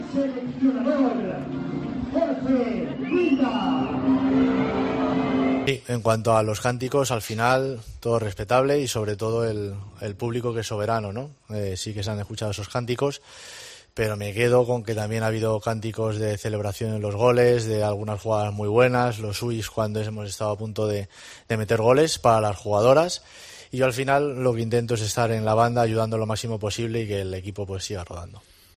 El entrenador de la Selección femenina ha respondido, en rueda de prensa, a los pitos y gritos de dimisión.